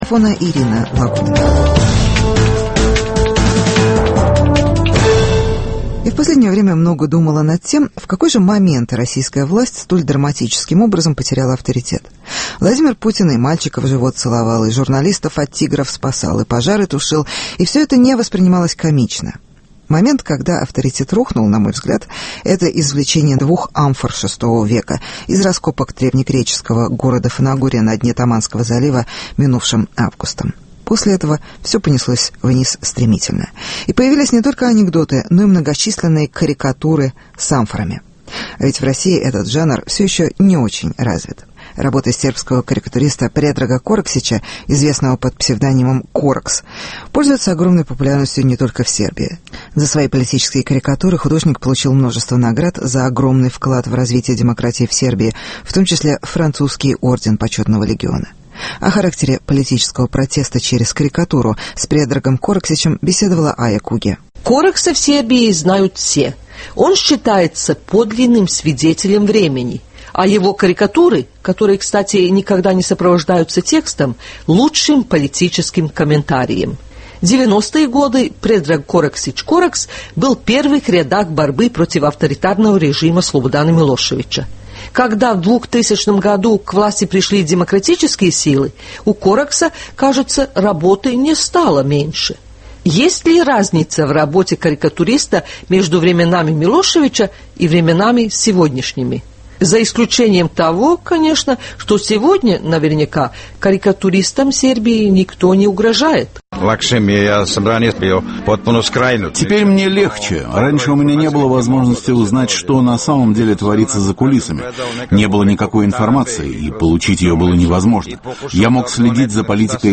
Карикатура как образ жизни. Интервью с сербским карикатуристом, который создал образ Милошевича